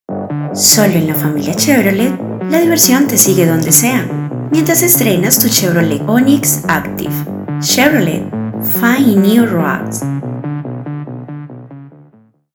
kolumbianisch
Sprechprobe: Sonstiges (Muttersprache):